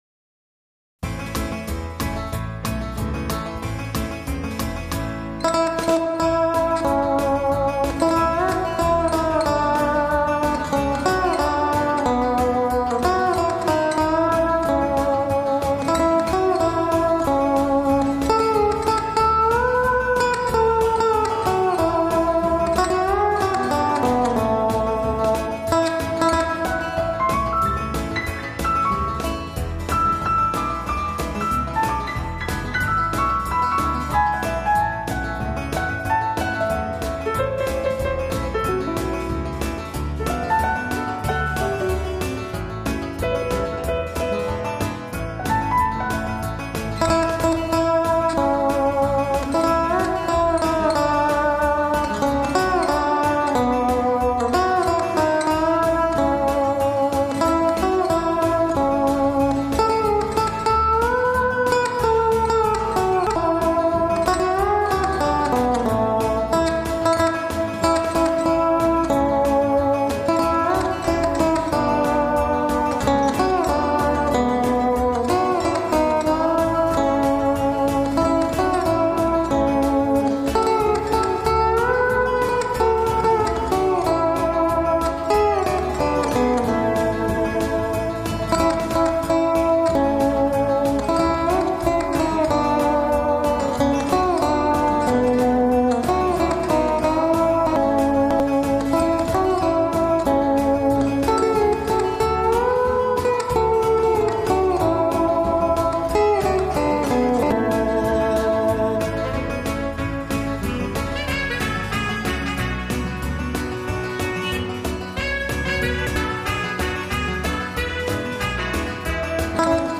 0168-吉他名曲划船曲.mp3